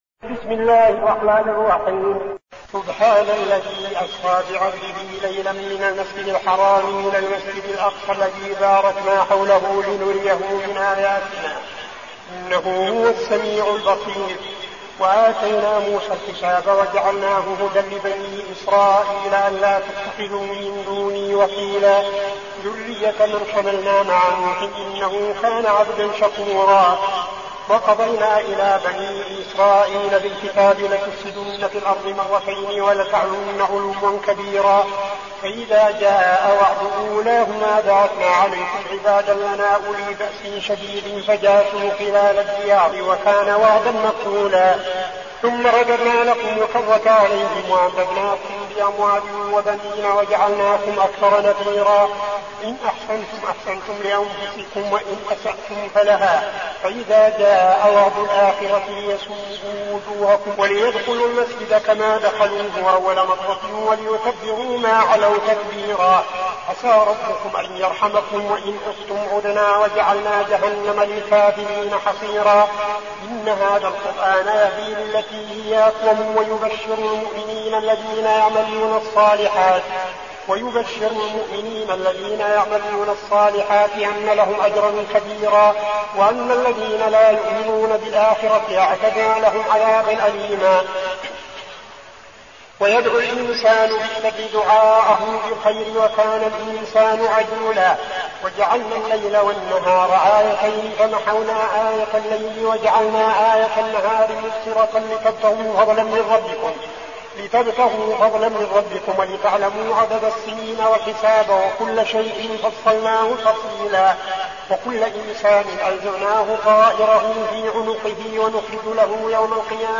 المكان: المسجد النبوي الشيخ: فضيلة الشيخ عبدالعزيز بن صالح فضيلة الشيخ عبدالعزيز بن صالح الإسراء